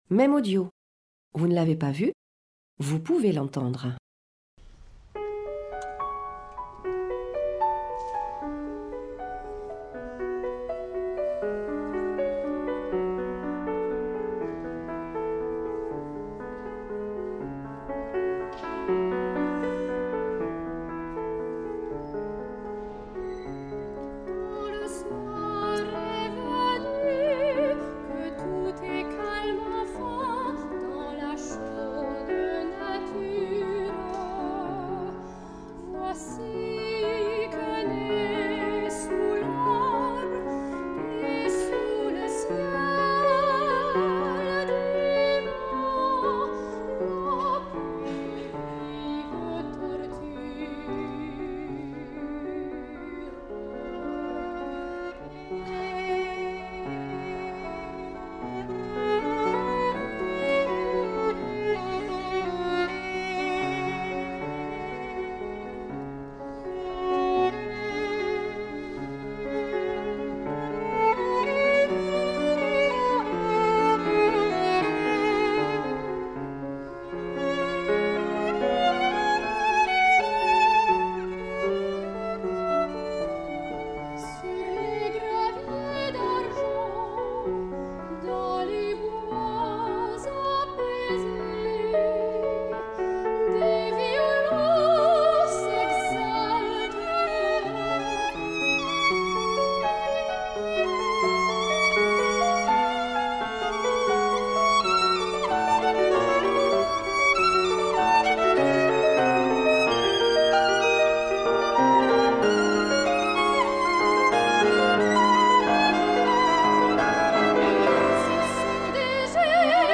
Un concert donné par des élèves en dernière année de conservatoire, à la bibliothèque d'étude et du patrimoine le 24/04/2025, à Toulouse.
Concert
violon
voix
piano